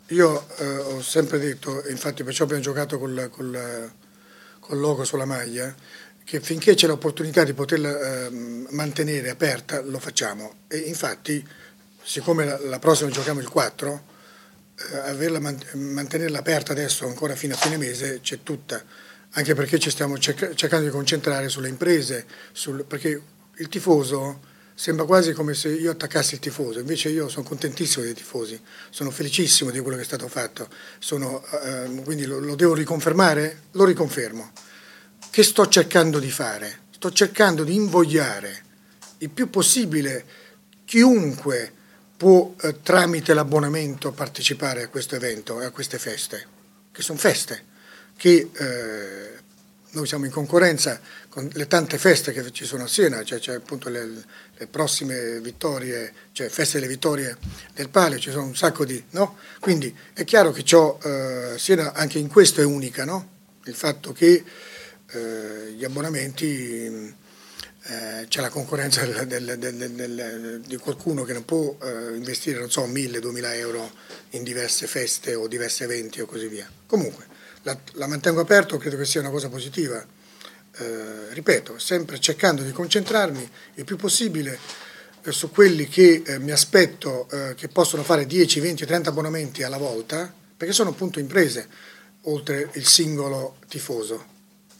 Di seguito gli audio della conferenza stampa